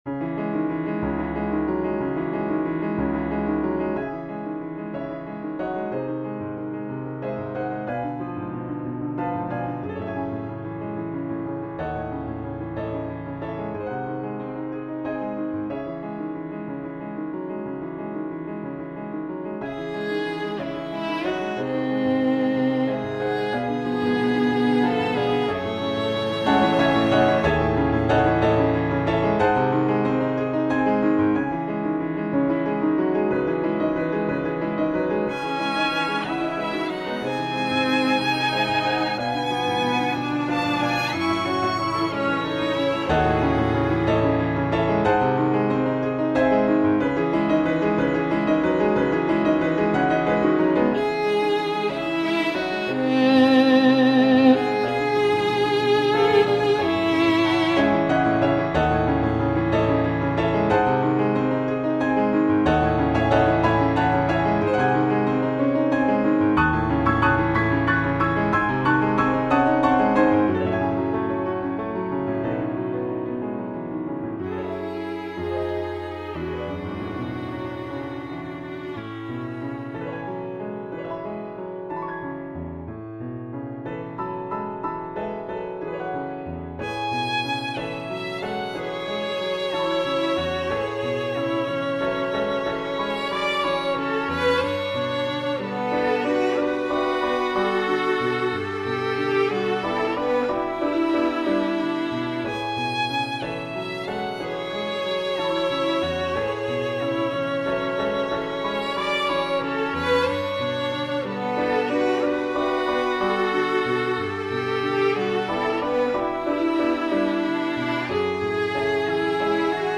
The piece reflects his oscillating emotions through a four-note motive repeated with melodic and harmonic variations. It begins with a melancholic chorus featuring tense harmony, then moves an exploration of various emotions with delicate, frequently changing harmonies.
Listen (Computer Generated Midi)